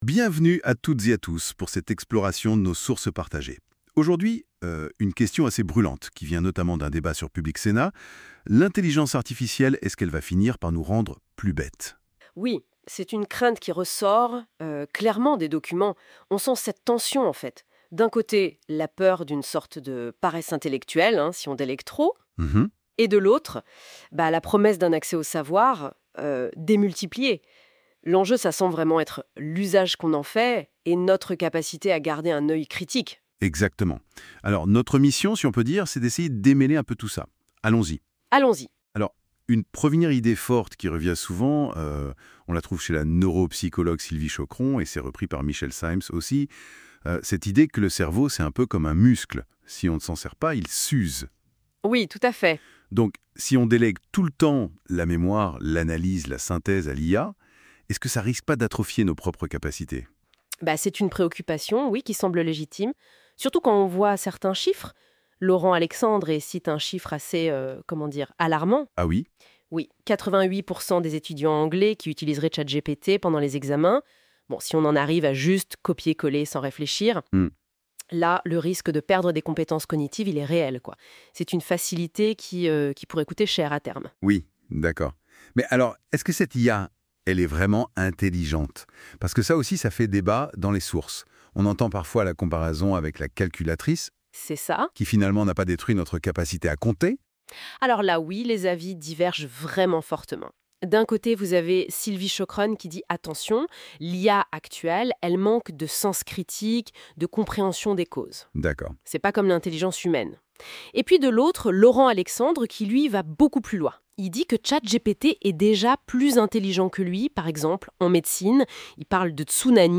[!Success]Qu’en pense l’IA ? Ecoutez la conversation !